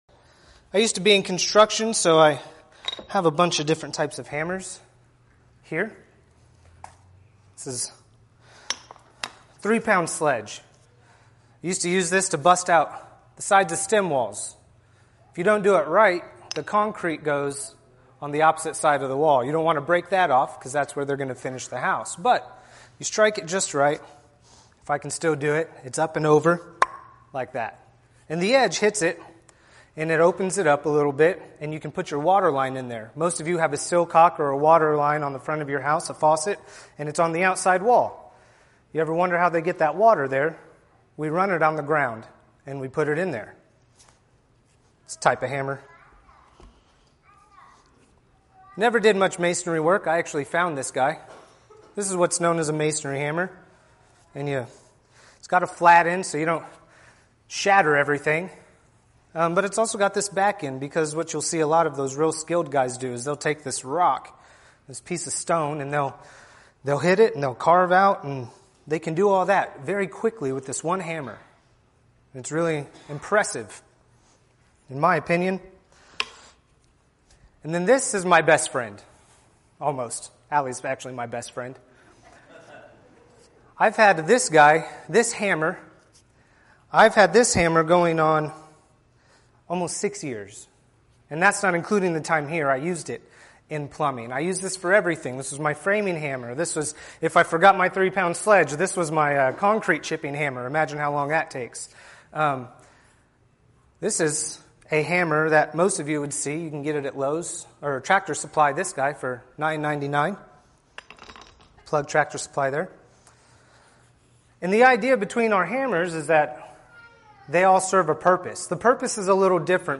Podcasts Videos Series Sermons We Have a Purpose